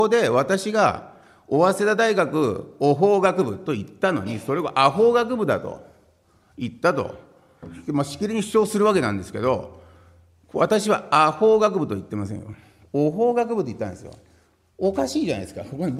資料3　井桁議員が行った計4回の懲罰に対する弁明　音声⑤　（音声・音楽：148KB）